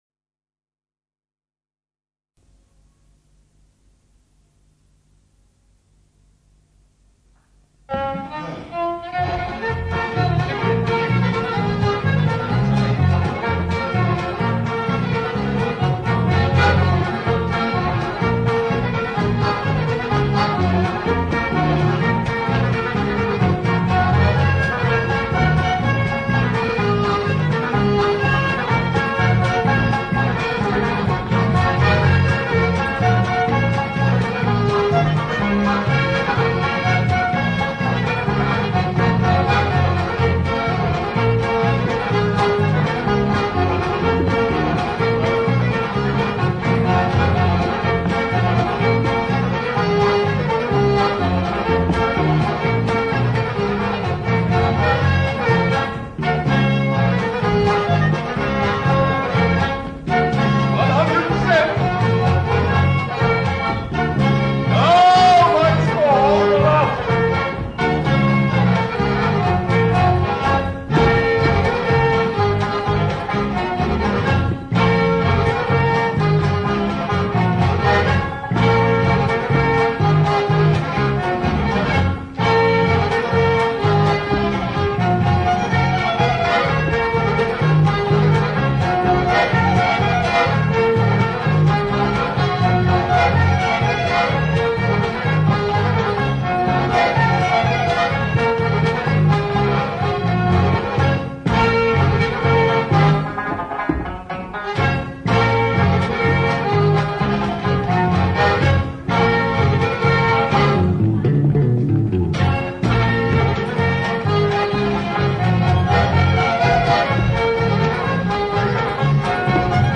Then there are a couple of sets recorded during a concert at the Little Theatre in Birkenhead.
set of polkas (Wally & Daisy's, Knick Knack) and